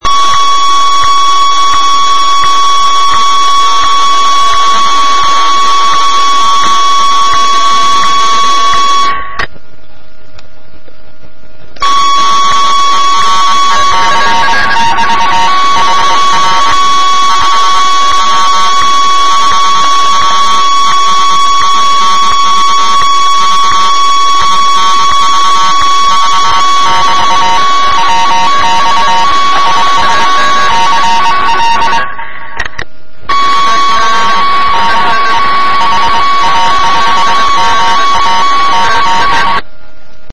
Naschvál jsem vybral záznam, kde je rušení od parazitního zázněje. Zařízení nemá žádné AVC a tak si musíte zvyknout i na určité zkreslení, ke kterému dochází při limitaci na NF zesilovači.